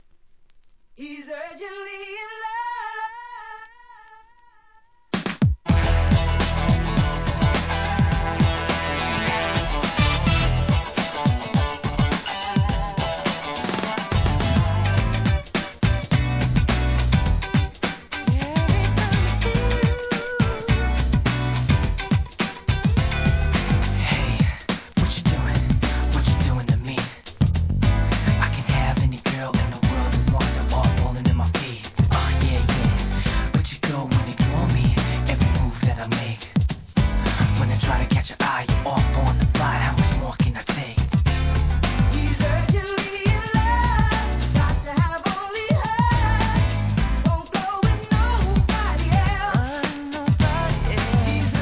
HIP HOP/R&B
胸弾むカッティングに弾ける爽快R&B大ヒットのREMIX!!